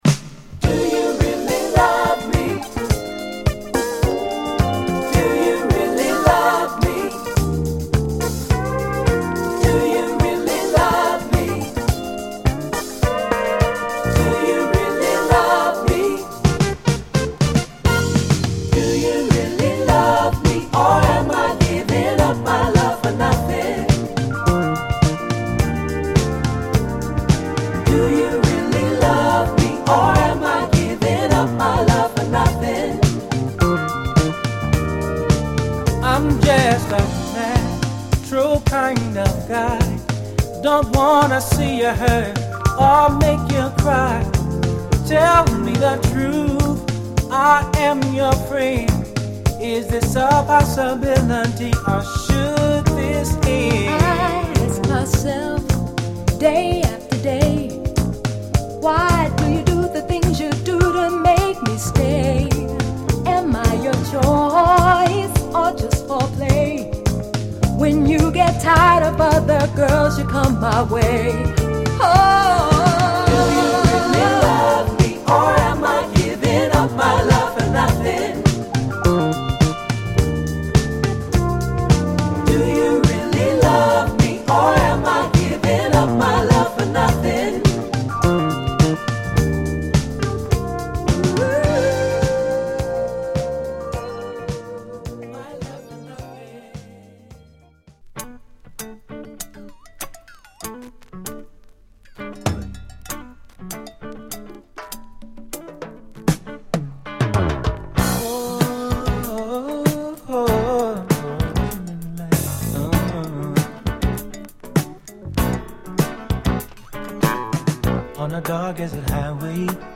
跳ねたブギートラックにドリーミーなシンセリードやエレピが煌めく、緩やかなメロウ・ミディアム・ダンサー！